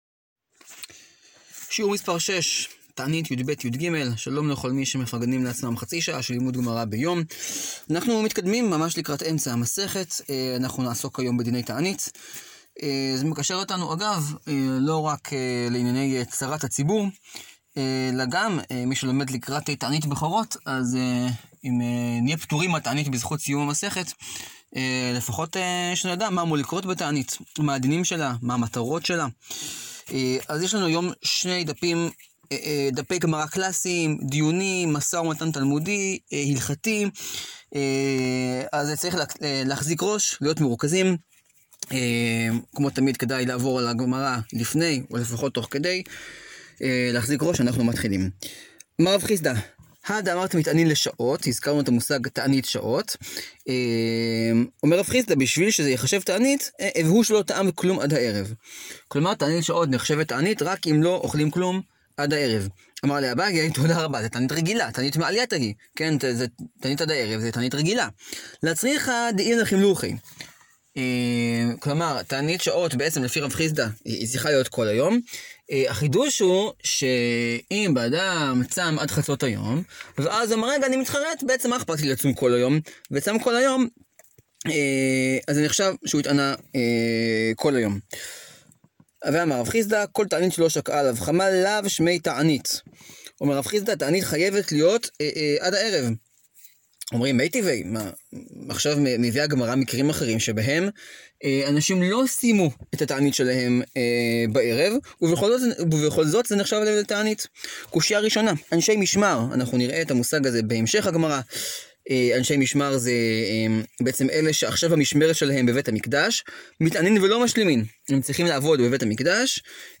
שיעור 6 להאזנה: מסכת תענית, דפים יב-יג.